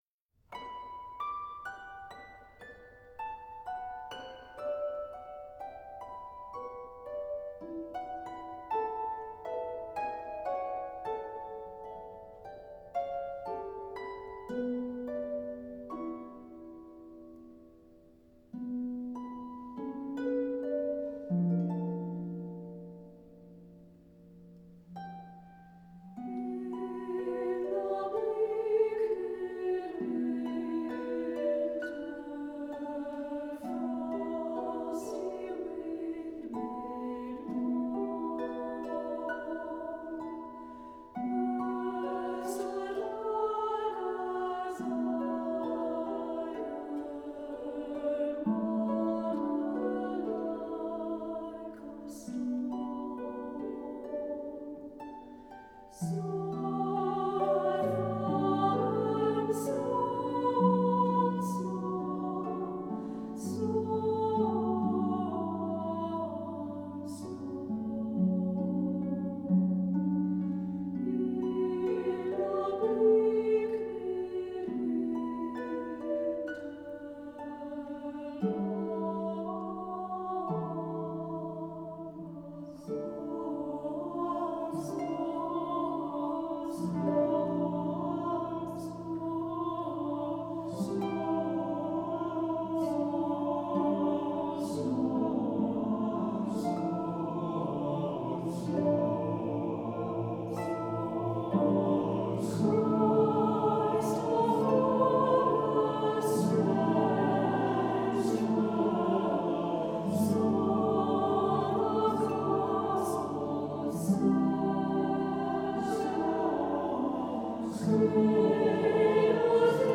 harp.
SATB chorus, 2 soloists, piano or harp